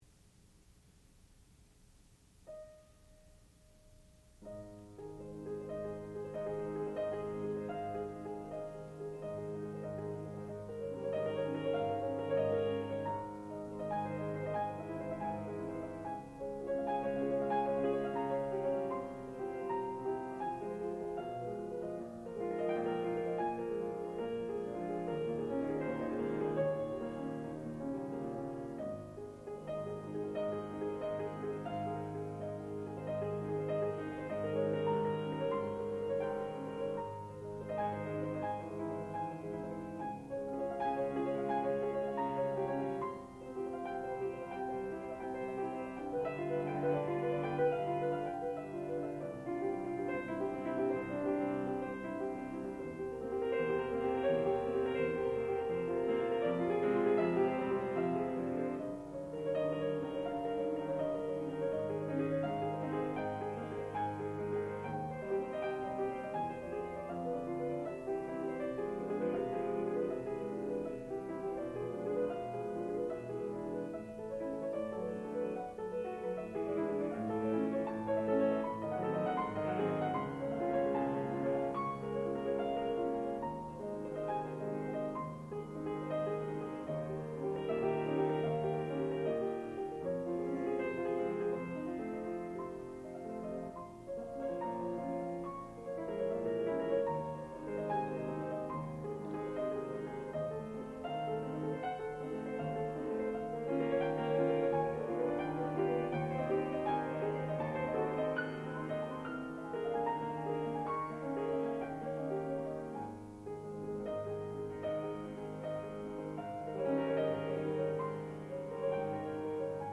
sound recording-musical
classical music
Student Recital
piano